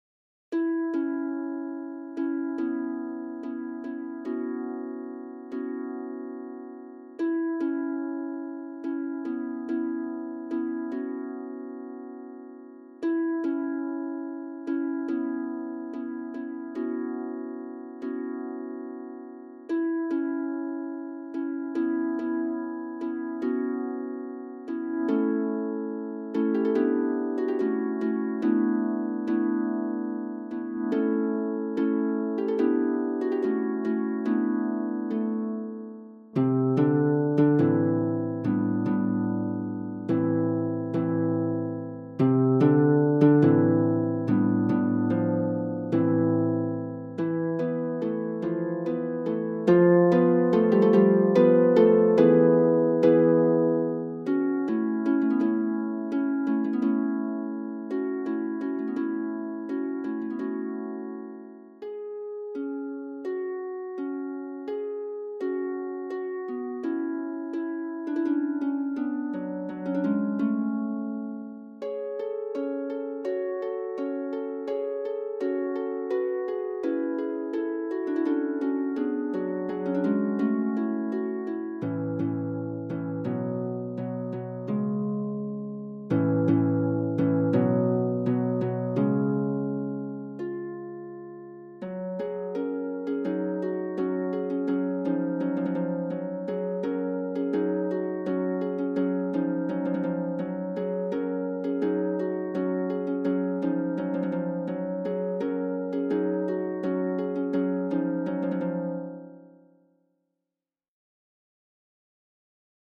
SATB + SATB (8 voix mixtes) ; Partition complète.
Genre-Style-Forme : Profane
Tonalité : modal